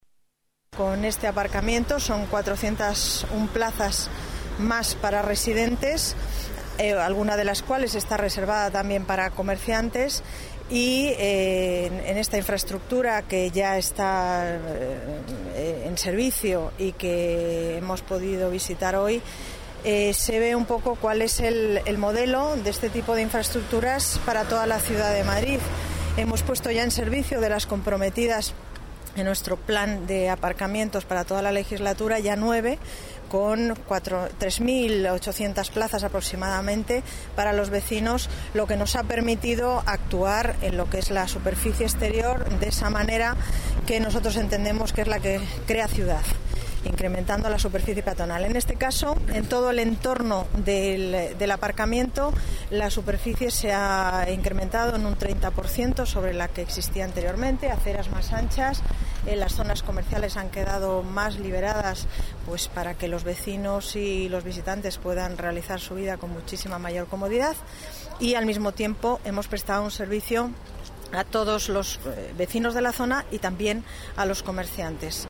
Nueva ventana:PazGonzalez, delegada Obras, en PAR Juan Ramón Jiménez